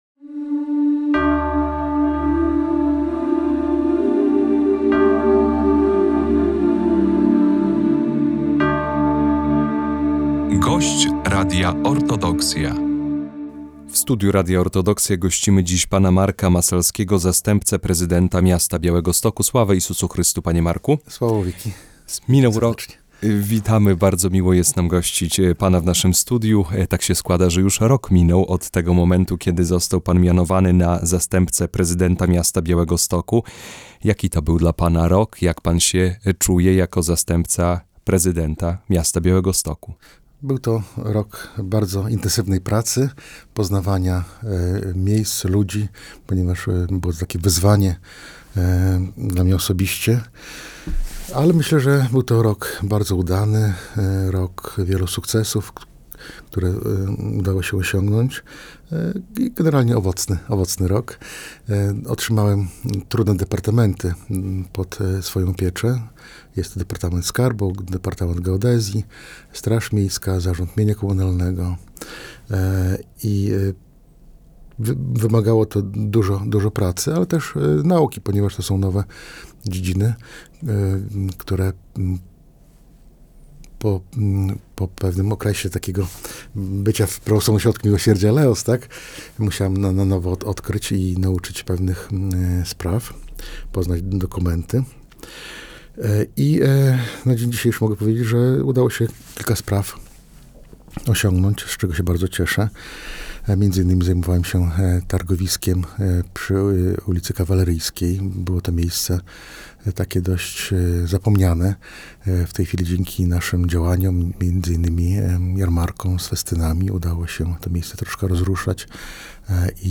Rozmowa z zastępcą prezydenta miasta Białegostoku Markiem Masalskim